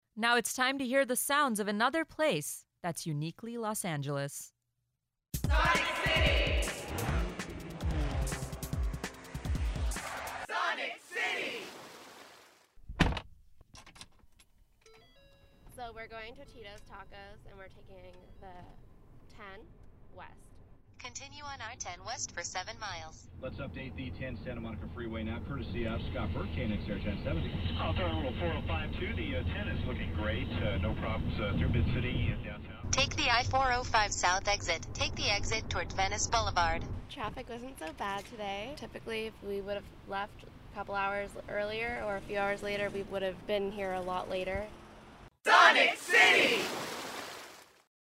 Sonic ID In the Car WH_0.mp3